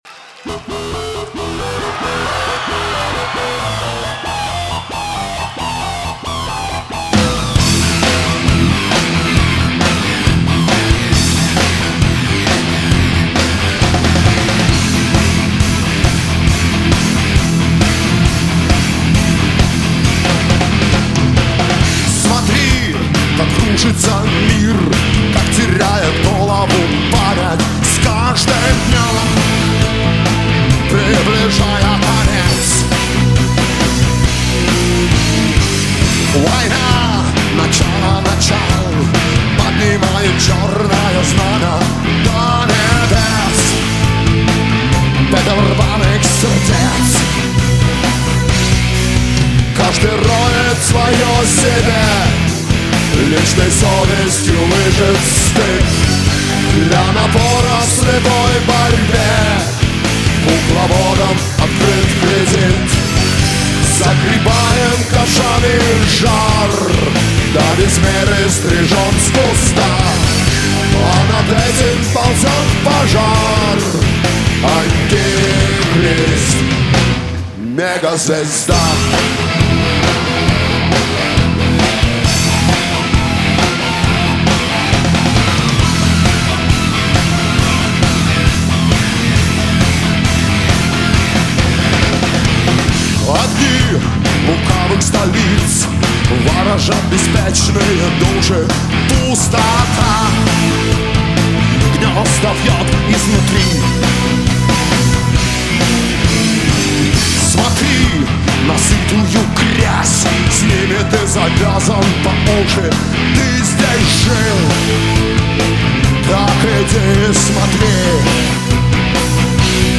LIVE
Песня